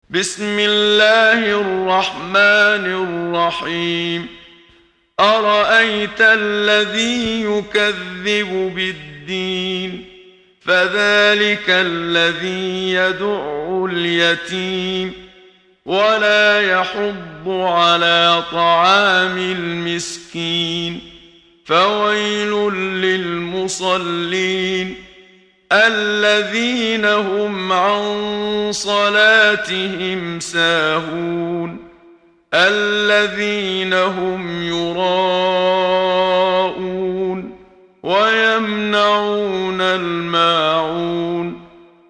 محمد صديق المنشاوي – ترتيل – الصفحة 9 – دعاة خير